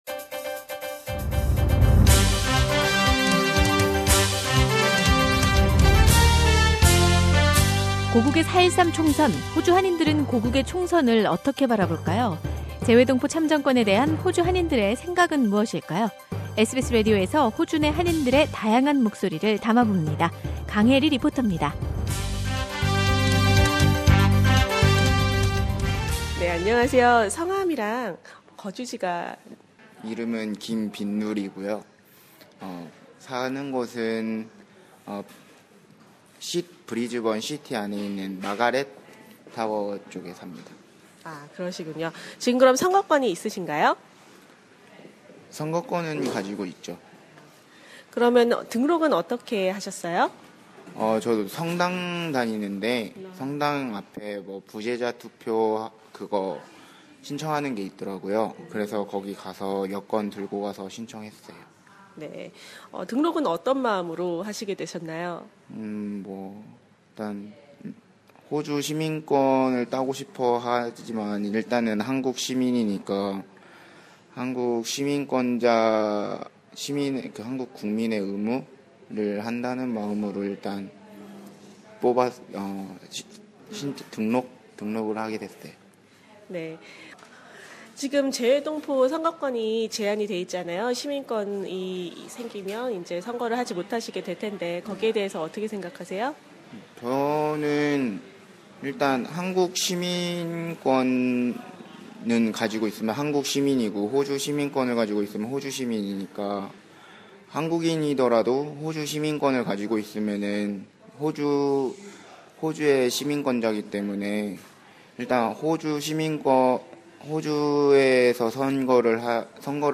Korean Progam presents a special voxpop series with those who have cast a vote in Australia for the Korea's general election which takes place on 13 April in Korea, to hear about their percetion on the overseas voting system and desire for Korean politicians.